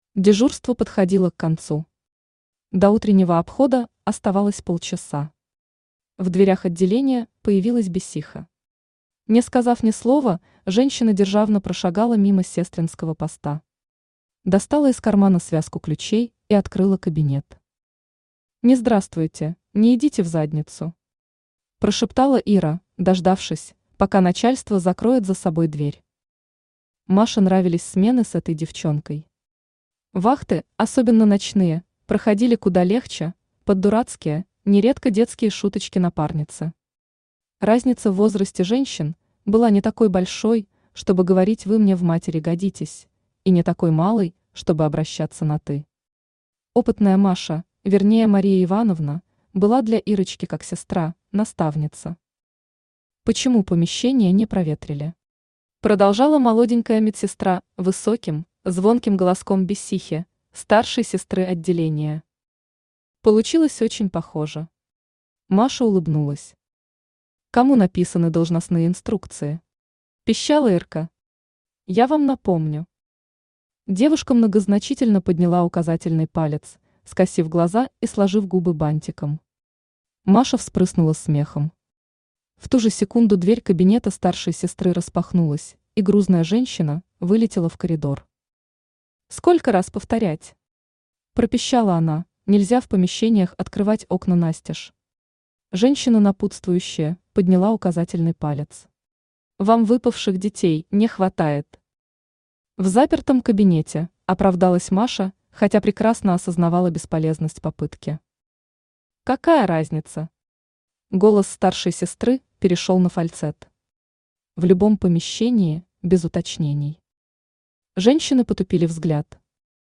Аудиокнига Фея | Библиотека аудиокниг
Aудиокнига Фея Автор ШаМаШ БраМиН Читает аудиокнигу Авточтец ЛитРес.